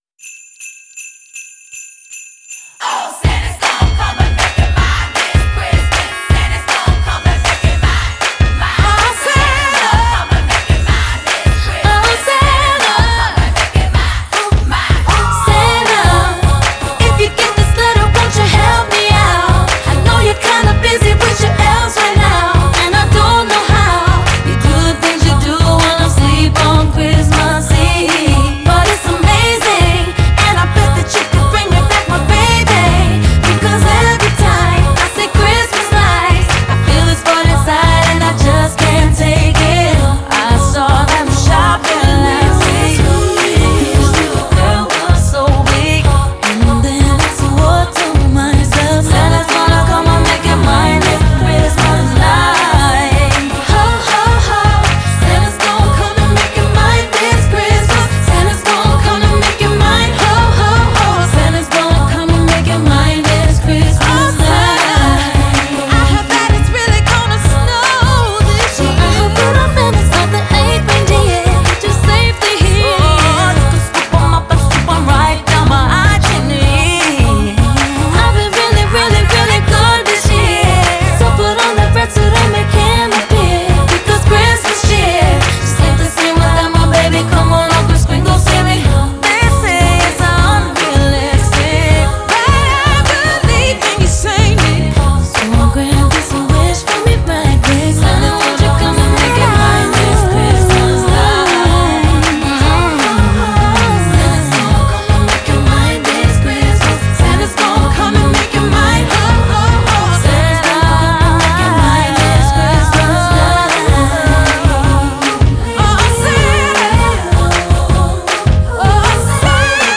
это зажигательная рождественская песня в жанре поп и R&B